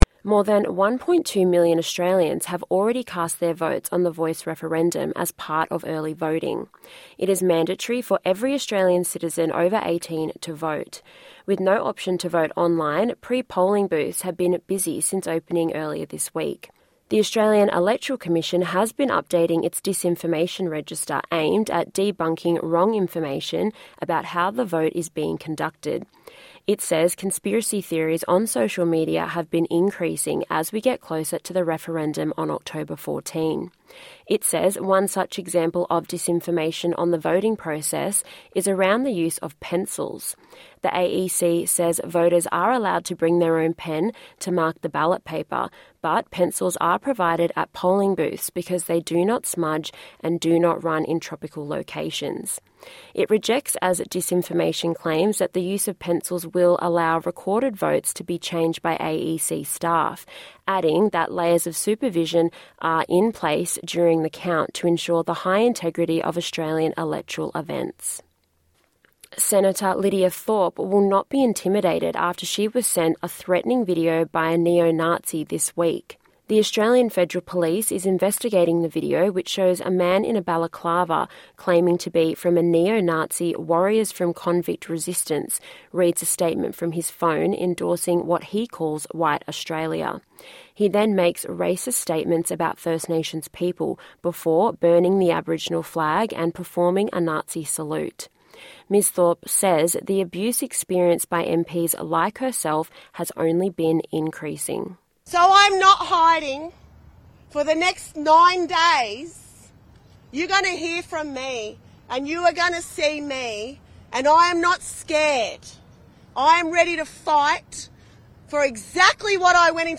NITV Radio News weekly wrap of the top stories of the week.